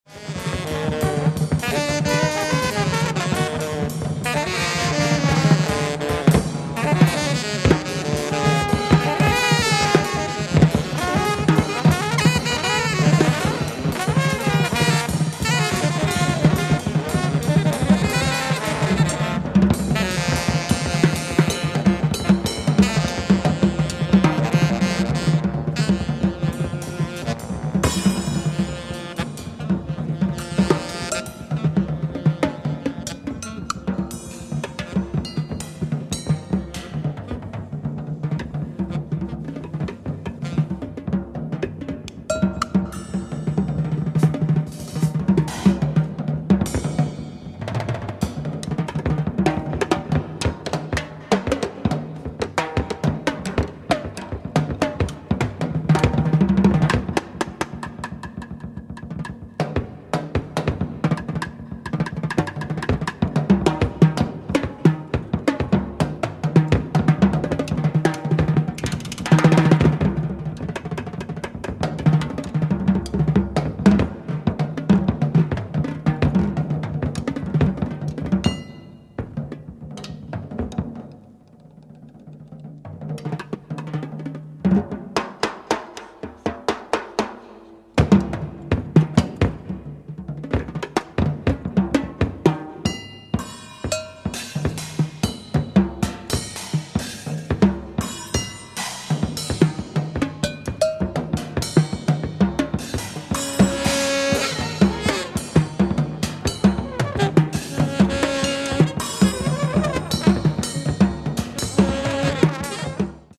Volatile and precise
tenor saxophone
trombone
drums
at Akadamie der Künste, Berlin, on January 12, 1972.